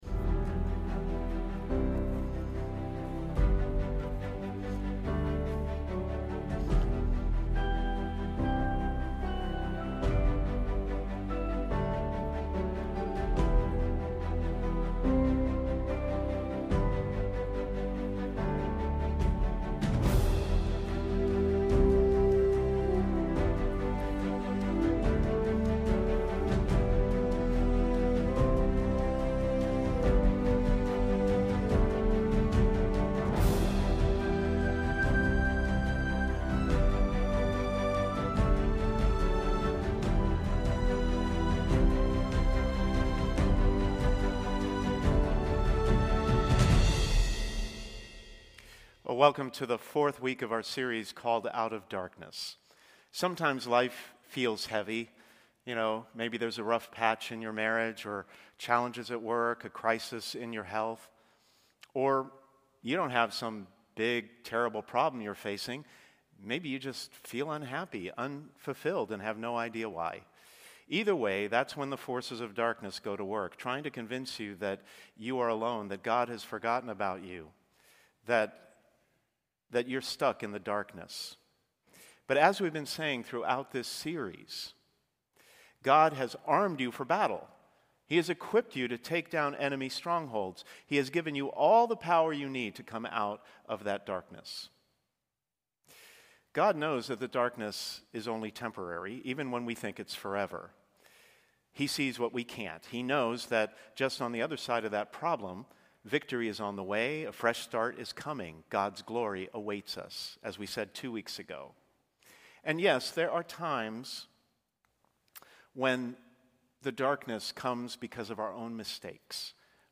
Sermons | St. Hilary Church